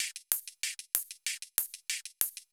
Index of /musicradar/ultimate-hihat-samples/95bpm
UHH_ElectroHatA_95-05.wav